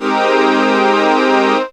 37kf01syn-g#.wav